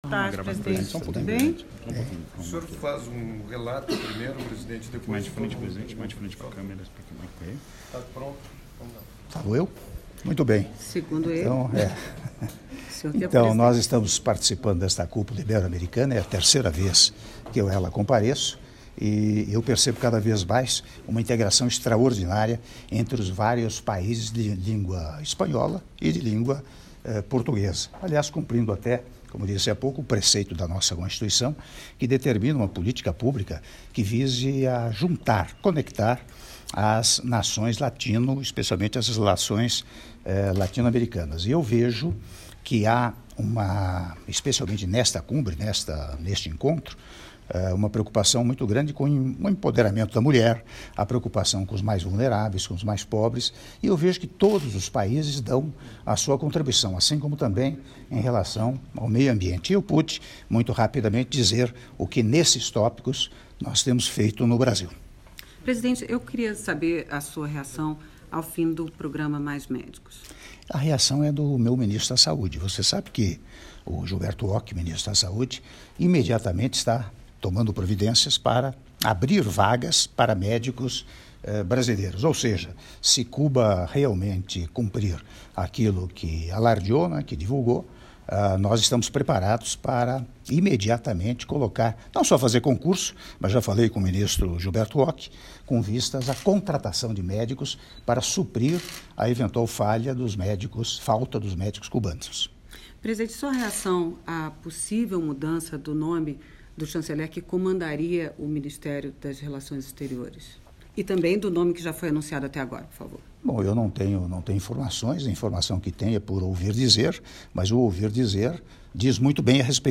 Áudio da entrevista coletiva concedida pelo Presidente da República, Michel Temer, após a Primeira Sessão da de Chefes de Estado e de Governo da XXVI Cúpula Ibero-Americana - Guatemala/Guatemala (03min30s) — Biblioteca